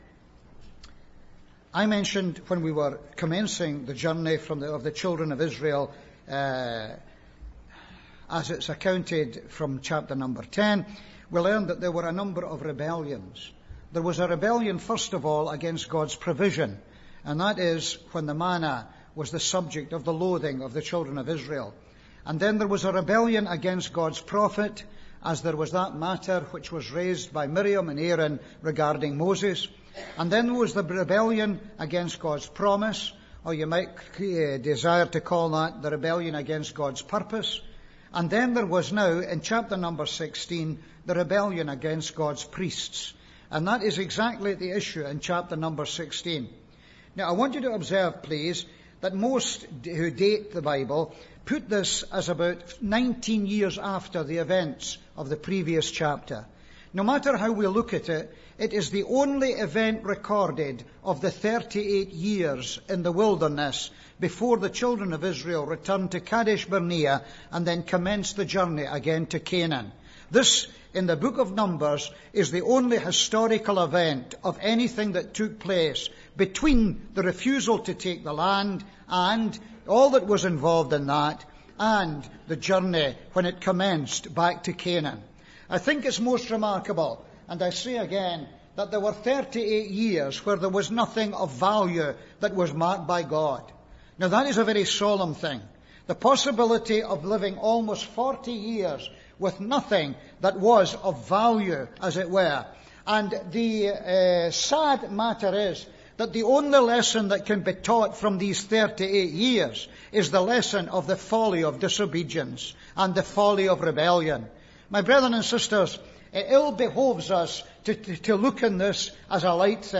Reading: Num 16:1-50 (Message preached in Stark Road Gospel Hall, Detroit, 2007).